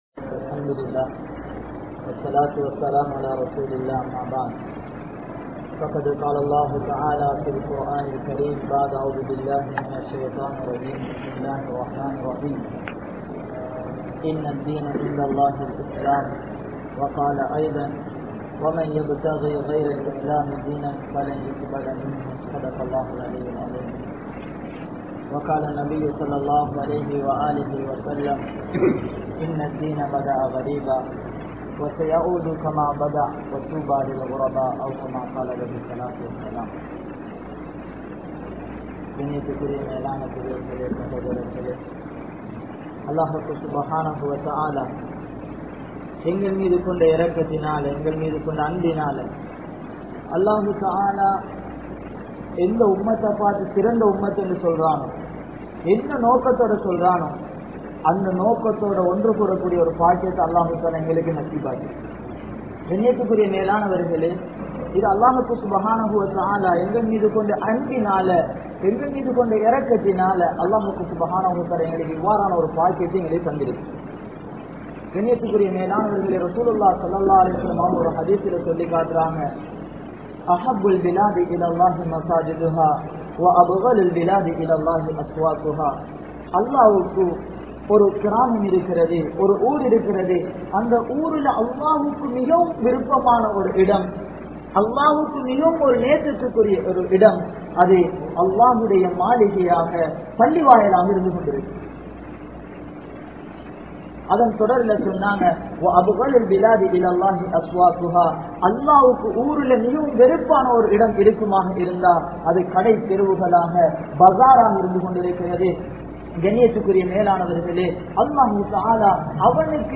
Islaathukkaaha Seitha Thiyaahangal (இஸ்லாத்துக்காக செய்த தியாகங்கள்) | Audio Bayans | All Ceylon Muslim Youth Community | Addalaichenai